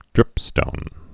(drĭpstōn)